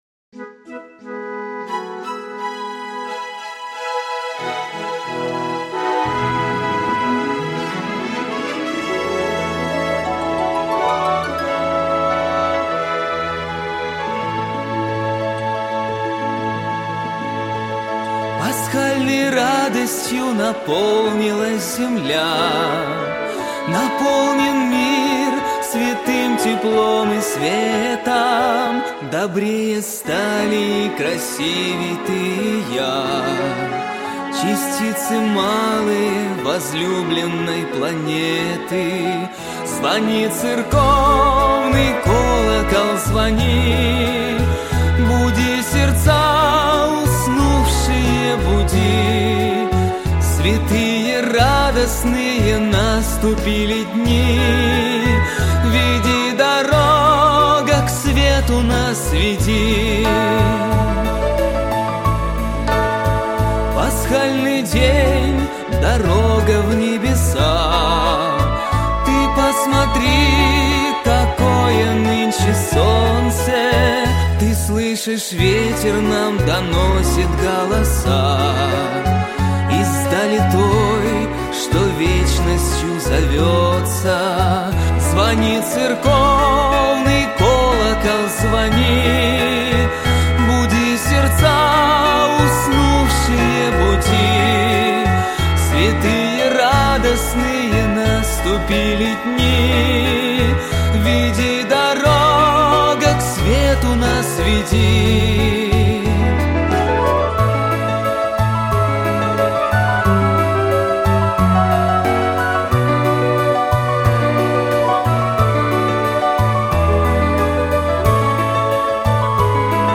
• Категория: Детские песни
теги: пасха, христианские песни